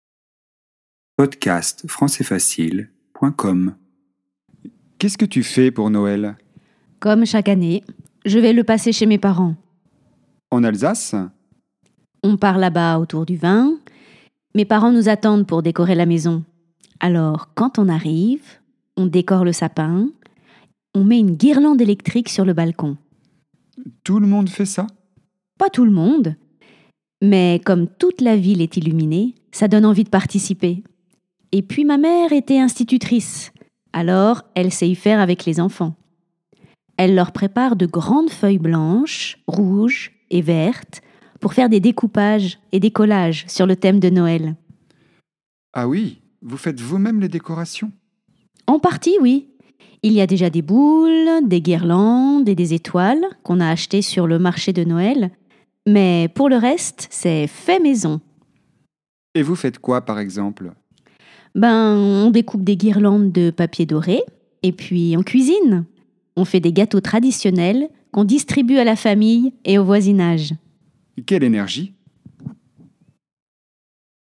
Dialogue :